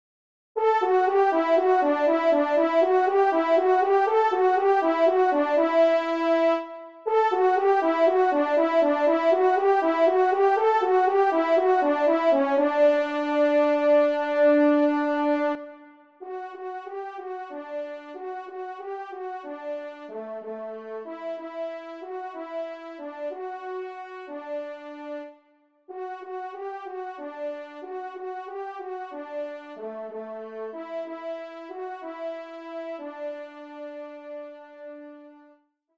1e Trompe           2e Trompe